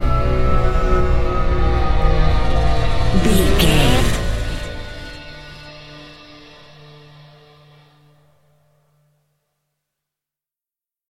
Thriller
Aeolian/Minor
Slow
piano
synthesiser
electric guitar